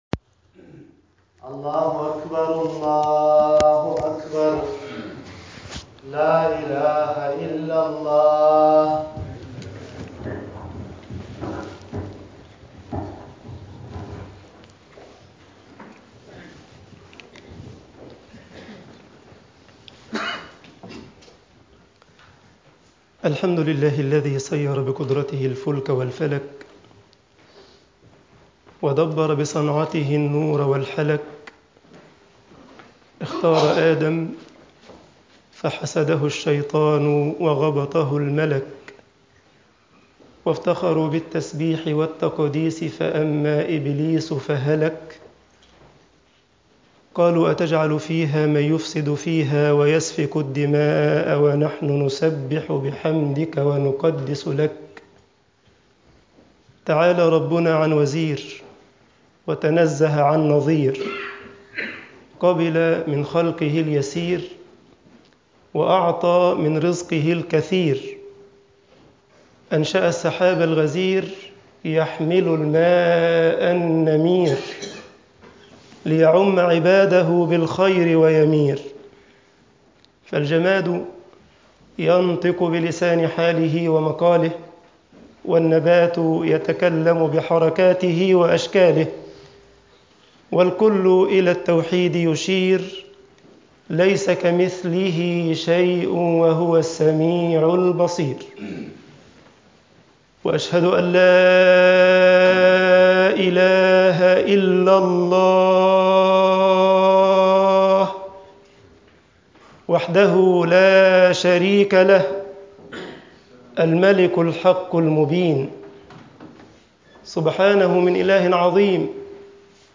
أقبلت العشر فكن من المشمرين - خطبه مسجد بلال
خطب الجمعة والعيد